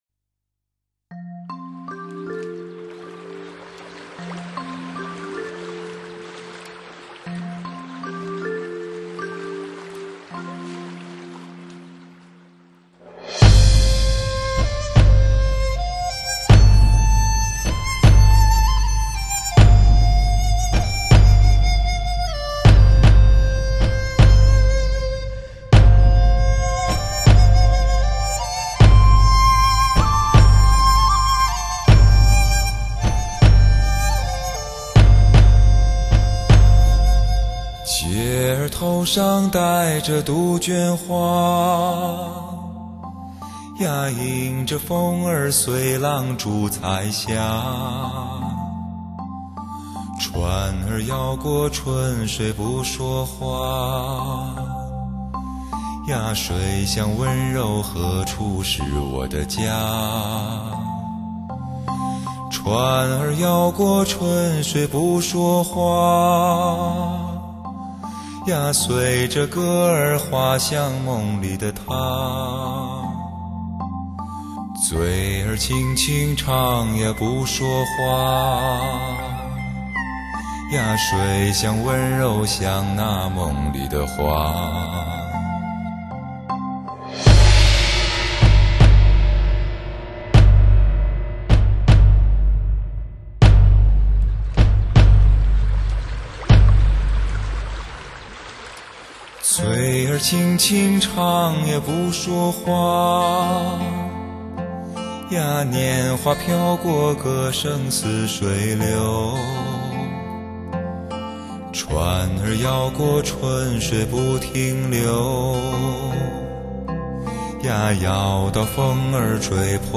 它凭借一整套独特的技术处理，让你能在普通CD机上得到LP密纹唱片的仿真音质感受。